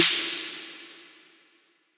Snare - Verb.wav